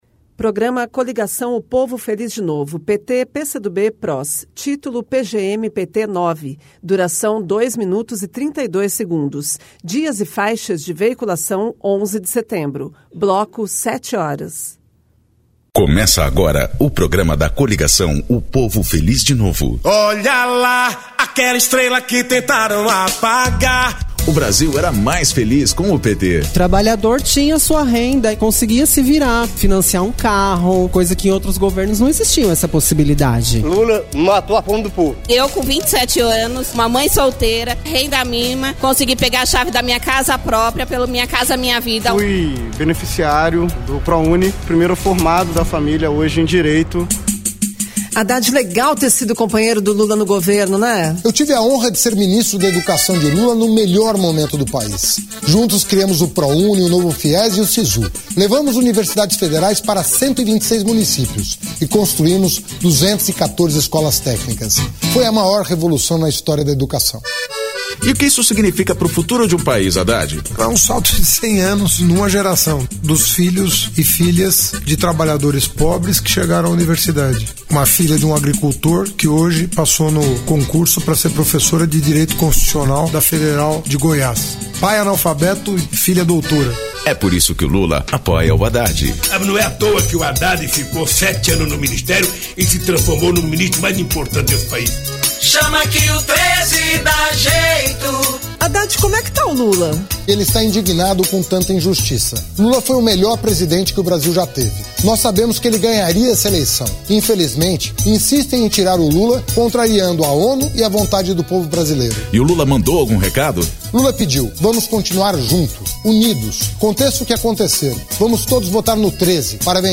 Descrição Programa de rádio da campanha de 2018 (edição 09) - 1° turno